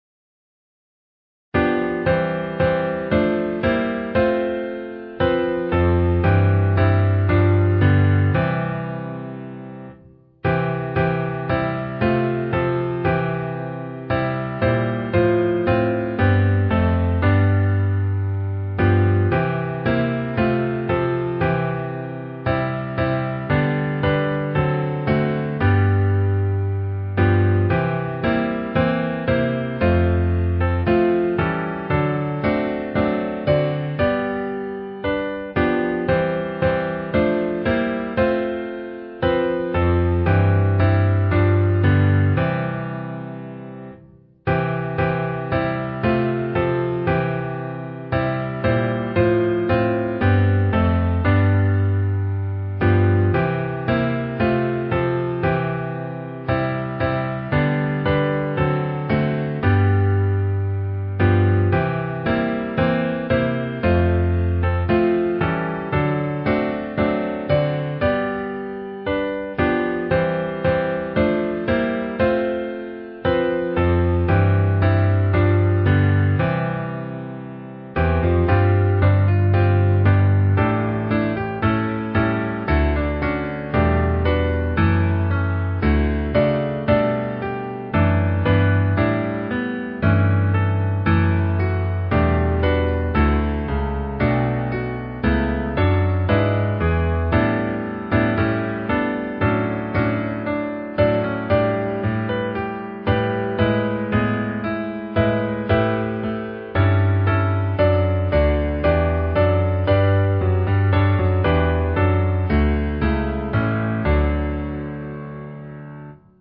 Key: D